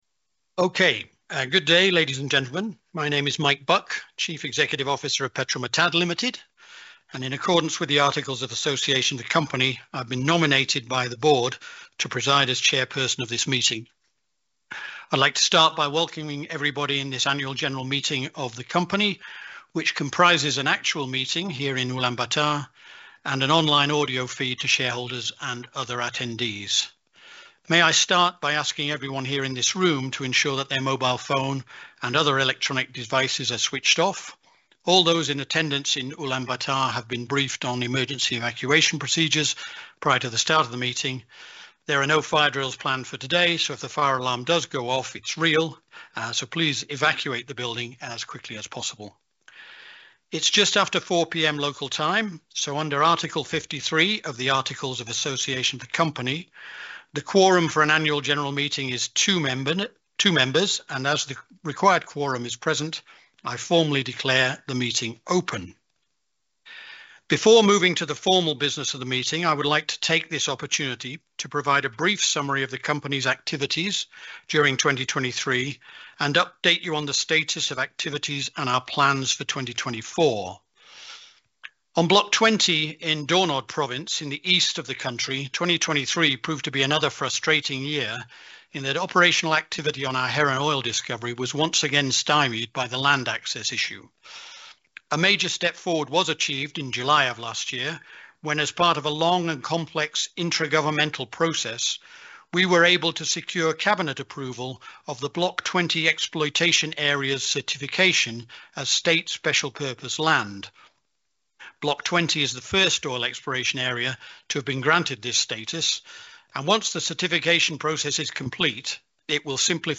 Presentation (video) by CEO